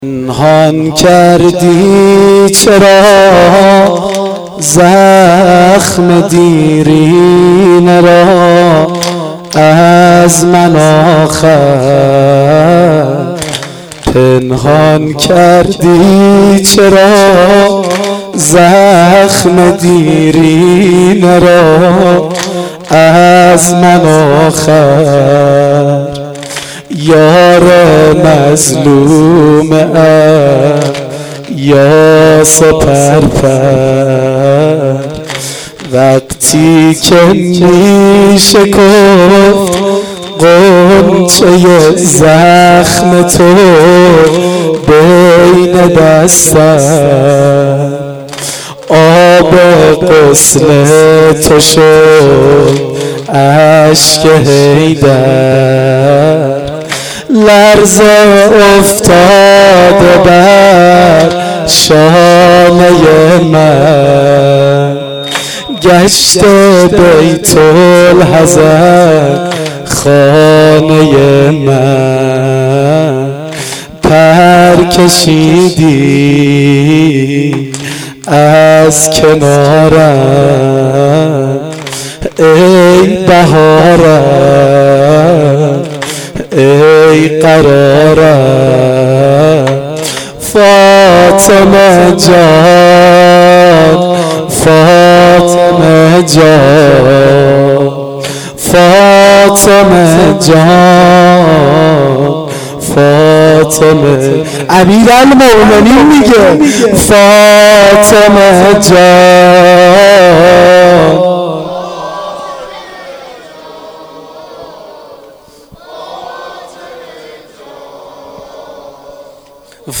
واحد سنگین شب دوم فاطمیه دوم